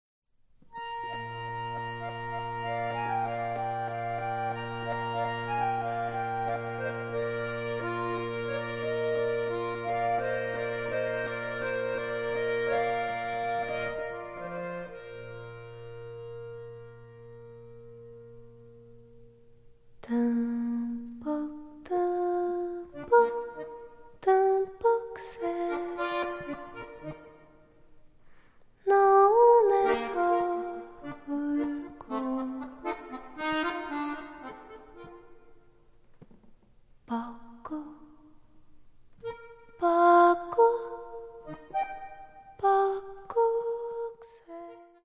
voice
piano
accordion